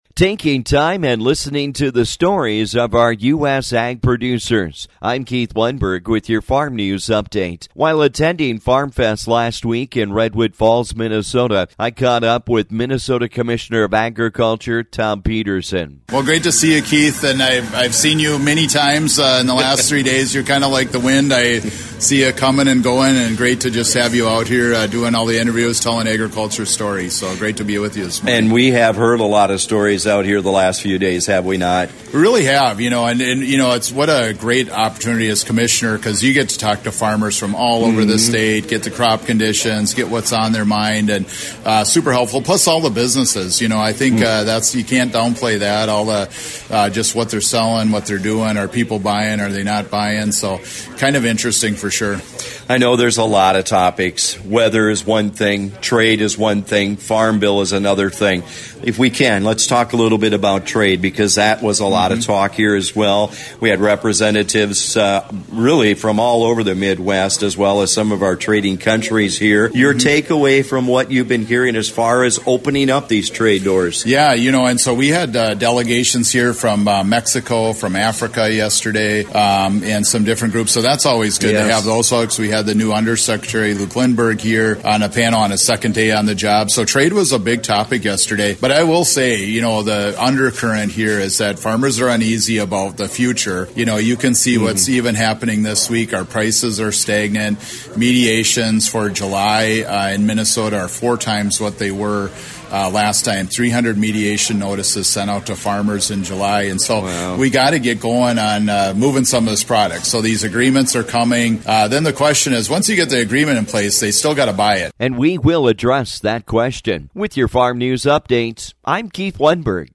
While attending Farm Fest last week, Thom Petersen, the Minnesota Commissioner of Agriculture enjoyed listening to the midwestern stories.